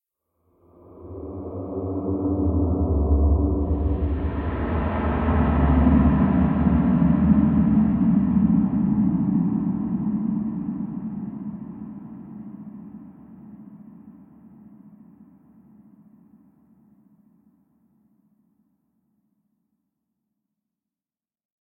В замке царит призрачная жуткая атмосфера
v_zamke__carit_prizrachnaya_zhutkaya_atmosfera_kww.mp3